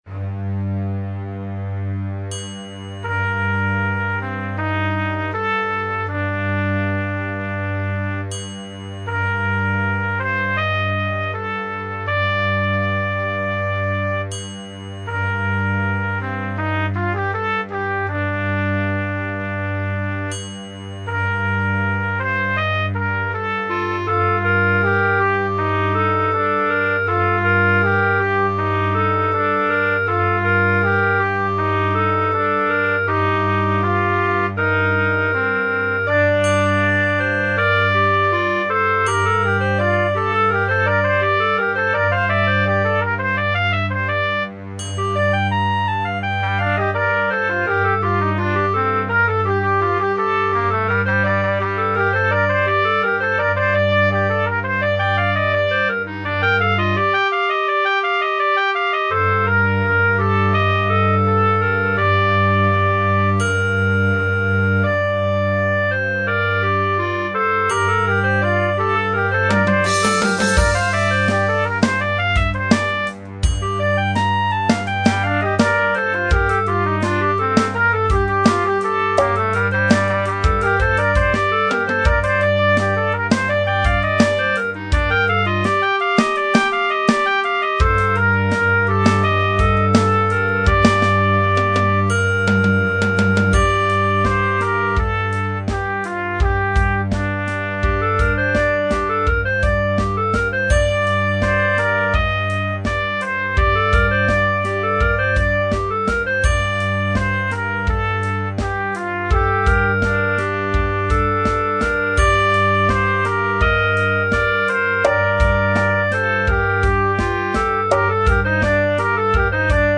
Interfaith Conference at Conference Point, Williams Bay, WI
INSTRUMENTAL (Trumpet, Clarinet, Set Drums, Didjeridoo or Contrabass)